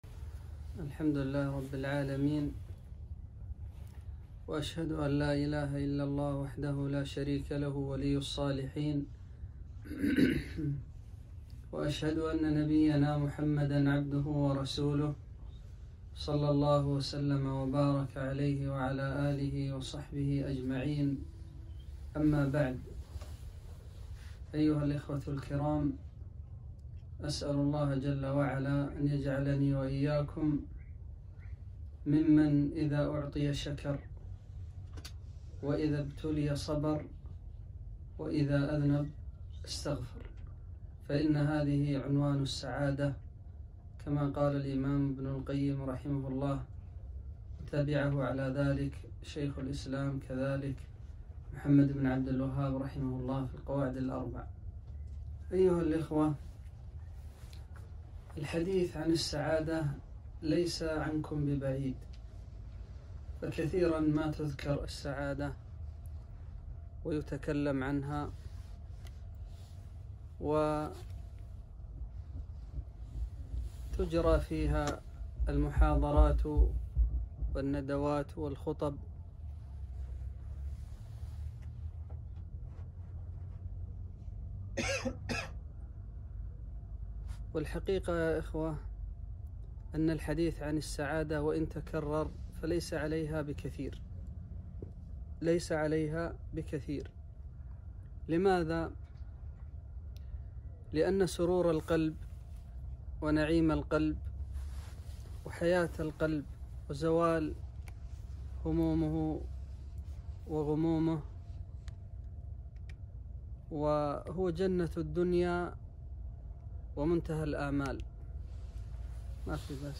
محاضرة - السعادة الحقيقية